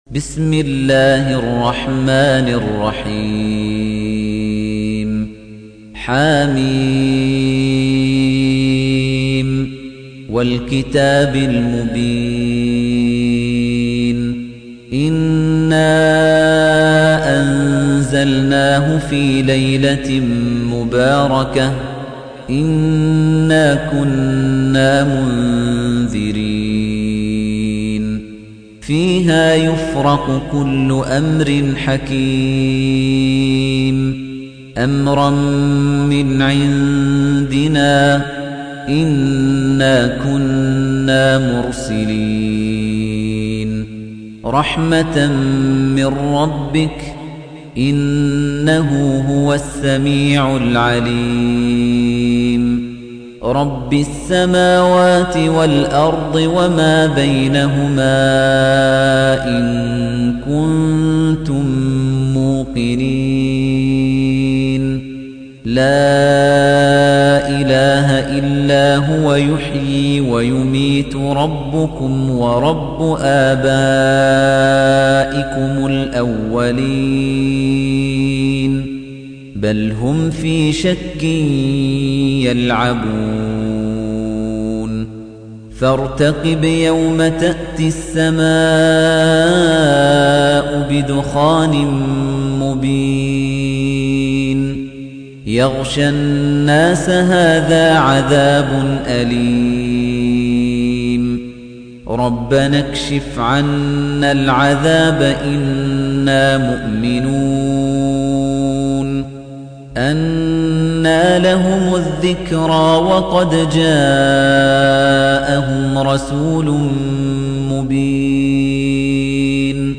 تحميل : 44. سورة الدخان / القارئ خليفة الطنيجي / القرآن الكريم / موقع يا حسين